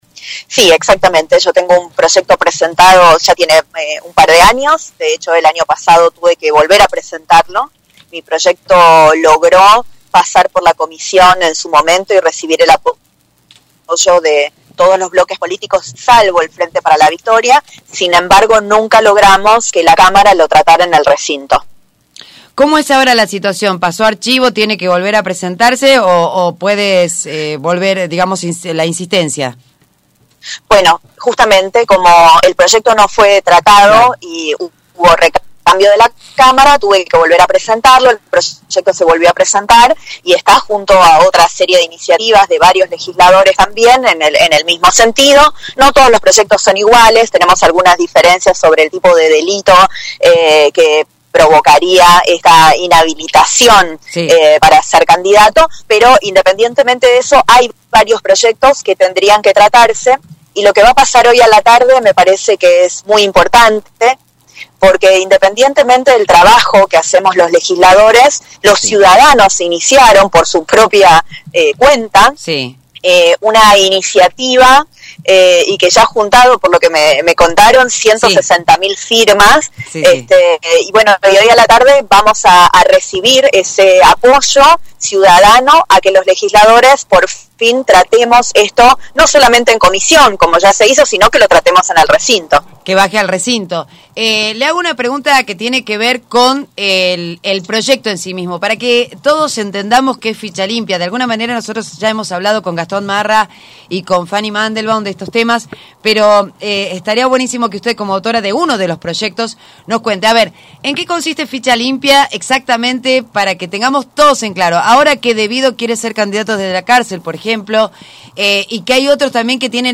Entrevista exclusiva a la diputada Silvia Lospennato sobre Ficha Limpia